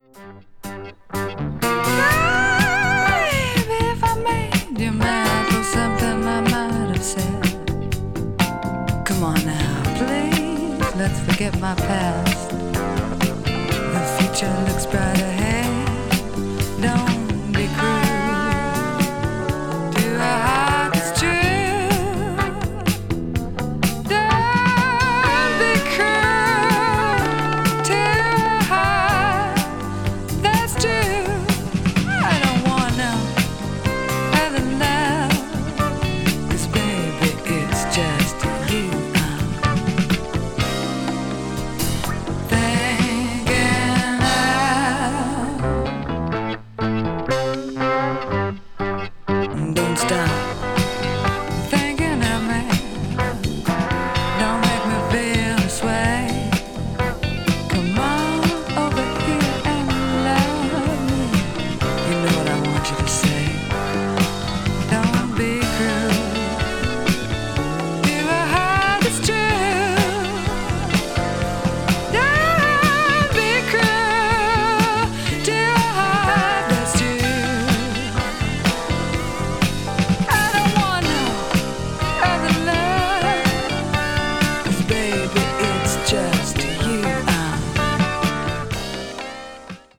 avant-garde   avant-rock   blues rock   jazz rock